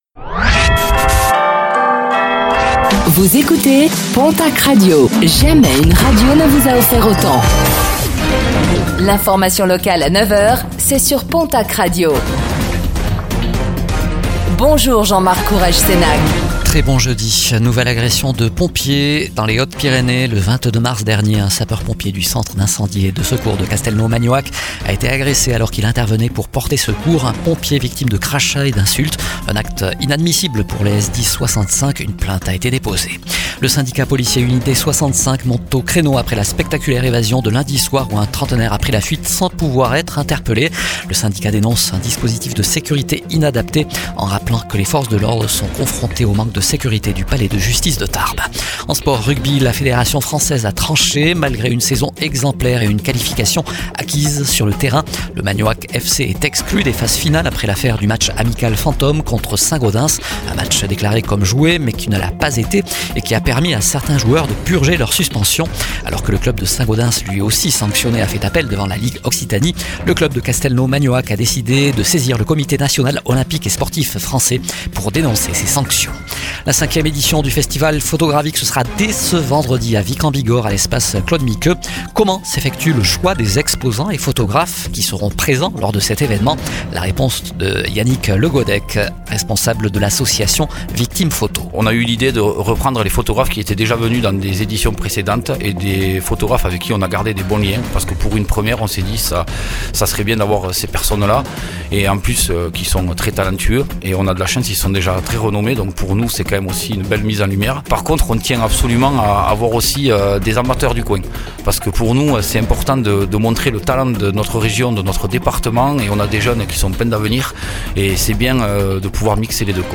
Réécoutez le flash d'information locale de ce jeudi 26 mars 2026